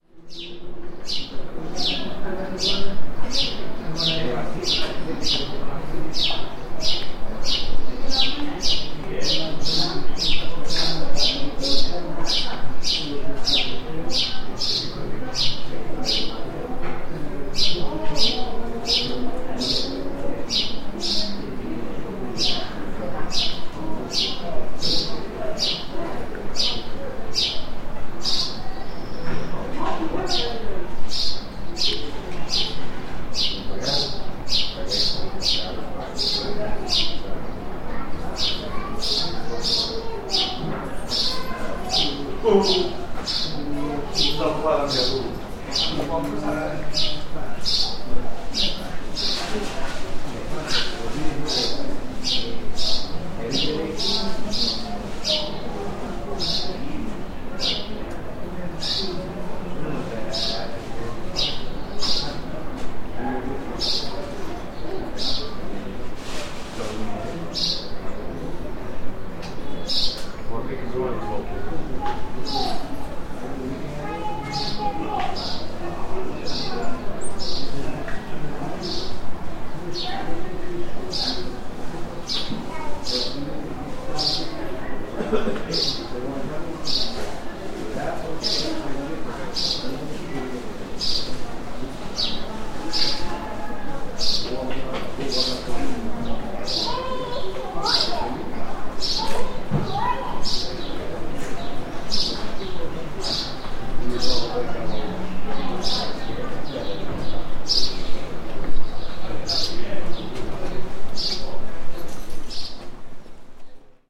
NODAR.00119 – Viseu: Rua Direita – Pássaro canta num beco
Gravação do canto solitário mas persistente de um pássaro. Gravado com Edirol R44 e um microfone shotgun Tellinga.
Tipo de Prática: Paisagem Sonora Rural
Viseu-Rua-Direita-Pássaro-canta-num-beco.mp3